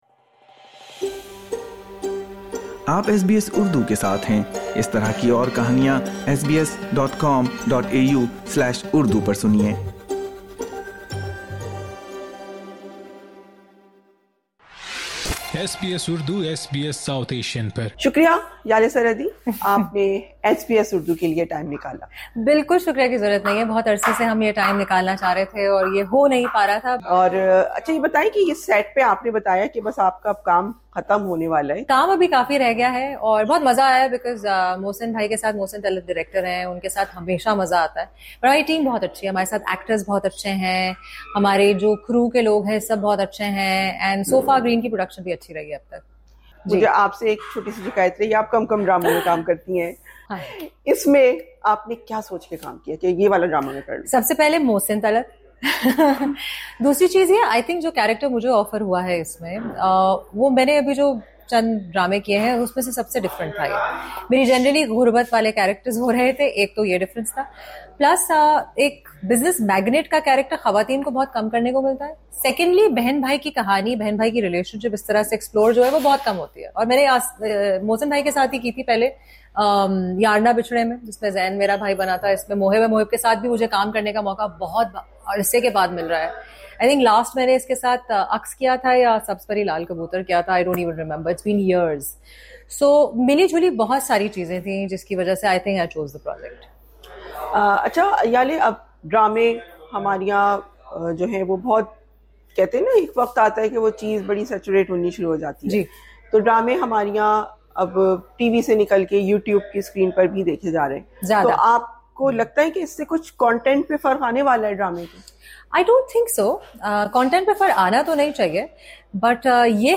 Find out in an exclusive conversation with SBS.